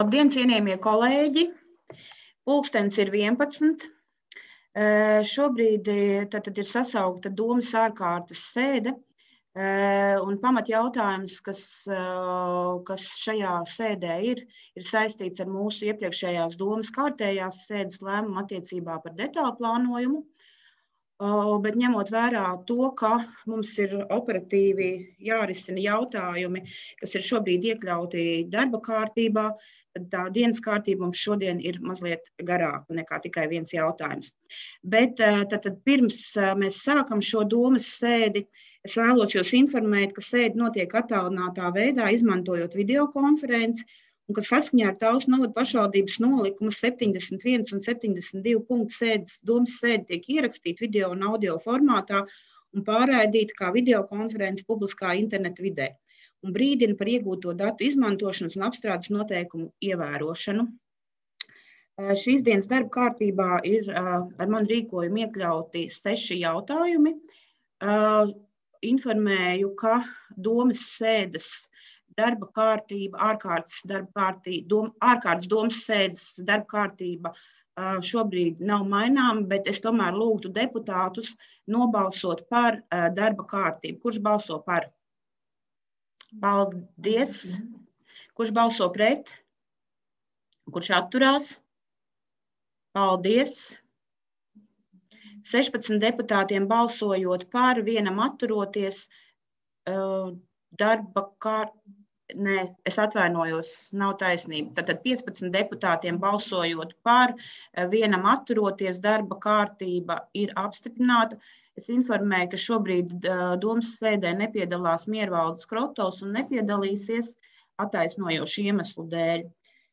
Balss ātrums Publicēts: 01.04.2021. Protokola tēma Domes sēde Protokola gads 2021 Lejupielādēt: 4.